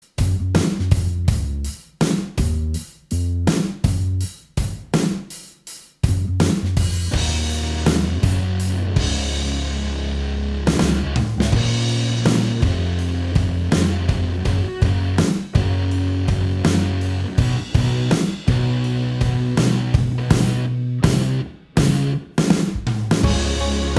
Minus Main Guitar Pop (2010s) 3:43 Buy £1.50